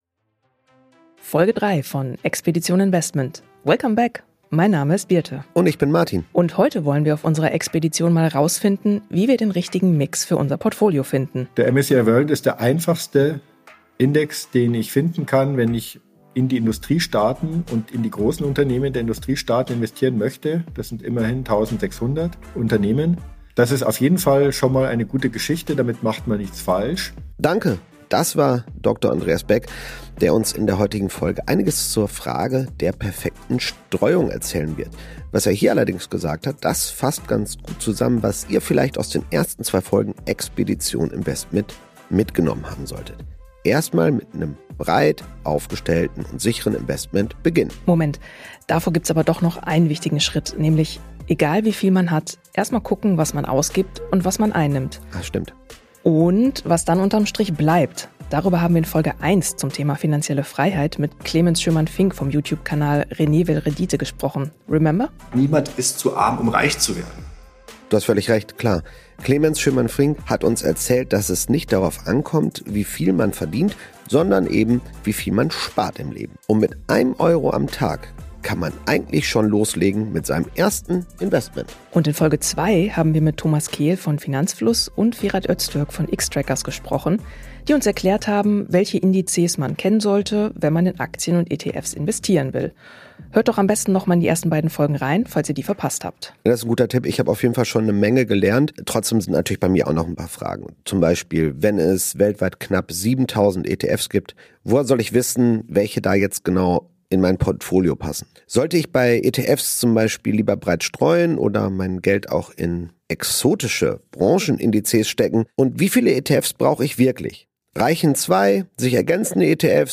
Mit einer Mischung aus Storytelling, Reportage und Interview